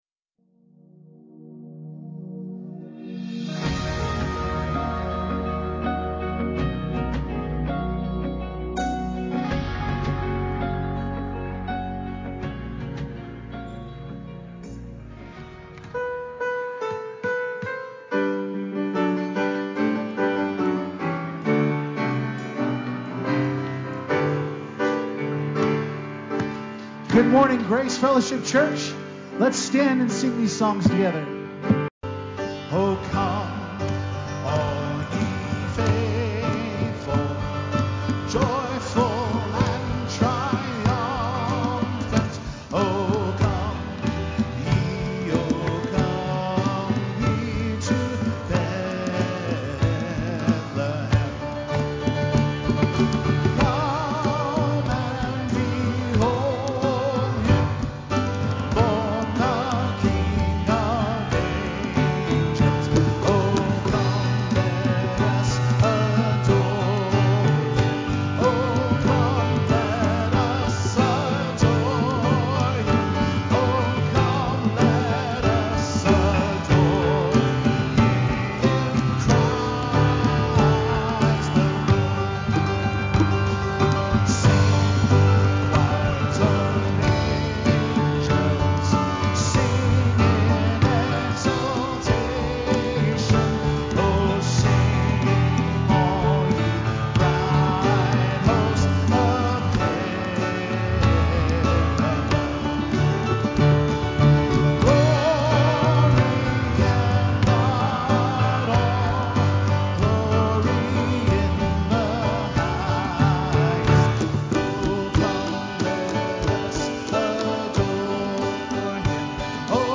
Love-Came-Down-FULL-SERVICE-CD.mp3